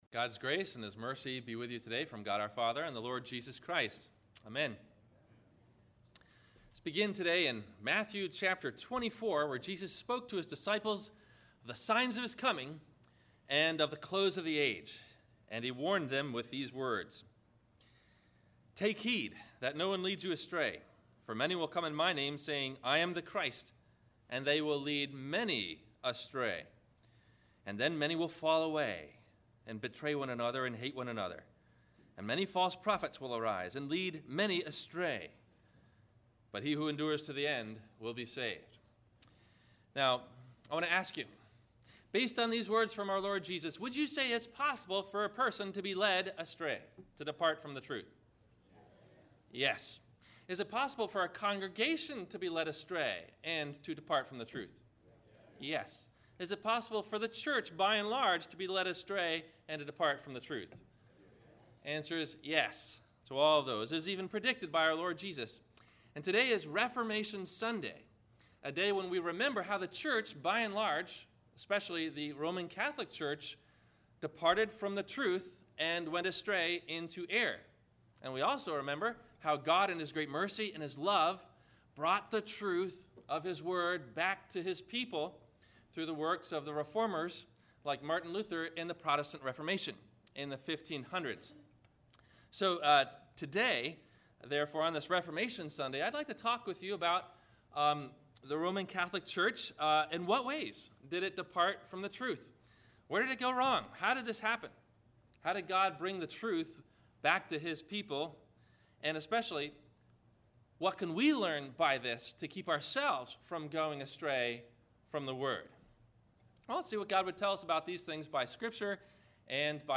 Luther's Discoveries Against Rome - Sermon - October 28 2012 - Christ Lutheran Cape Canaveral